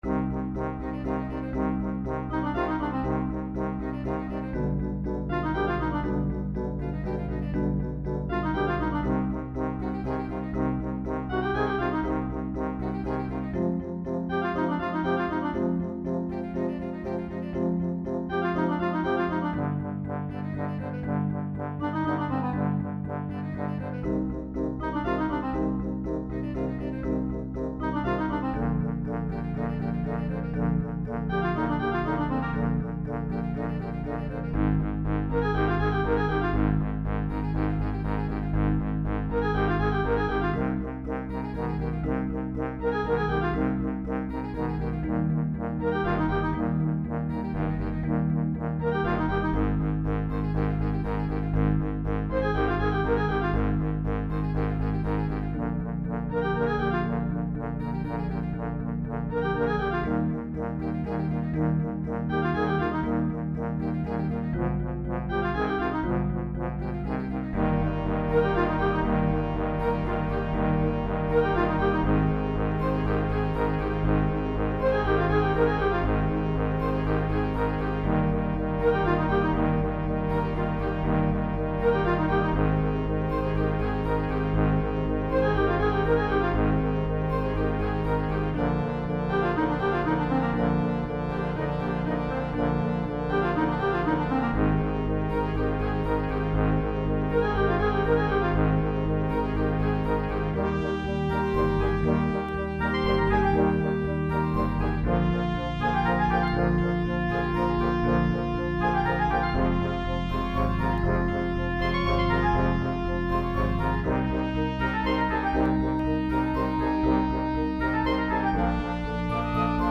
Bastrombone